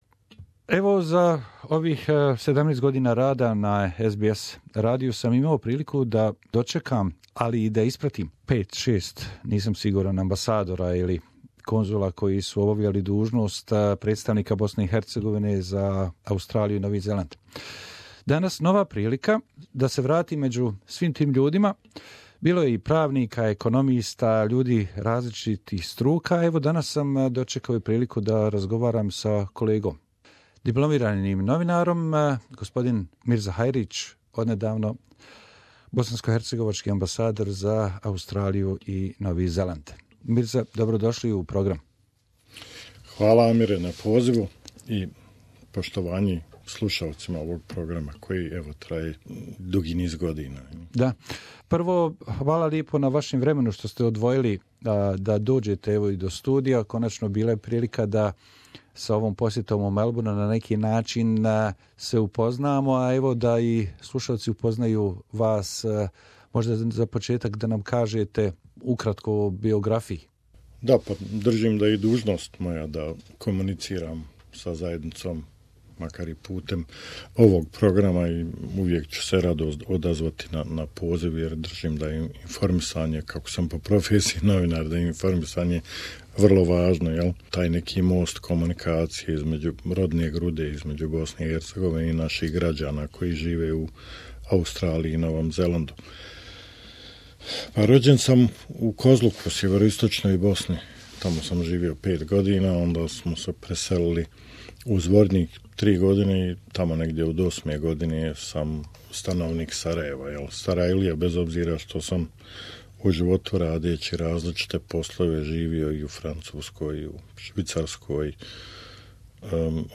The guest in our studio was the new Ambassador of Bosnia and Herzegovina in Australia, Mr. Mirza Hajric We talked about his first work experience in Canberra as well as the plans of the embassy of Bosnia and Herzegovina.